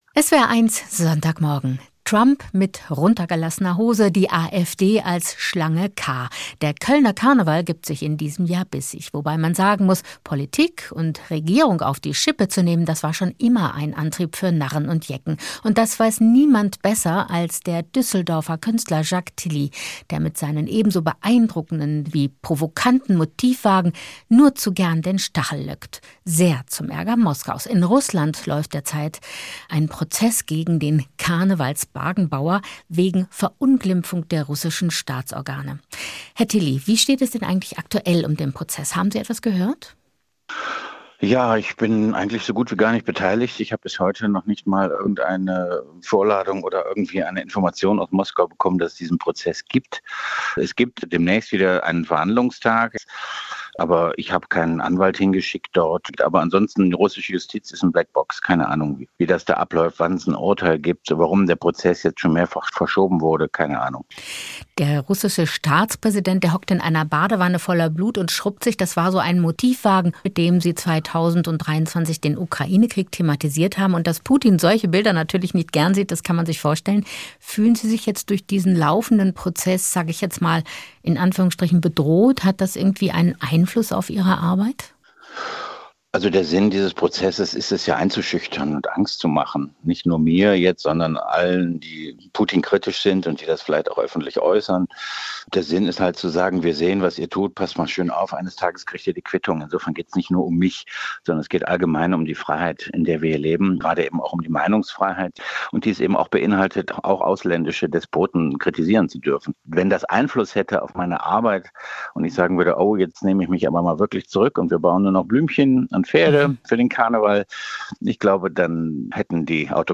Gespräch mit dem Düsseldorfer Wagenbauer Jacques Tilly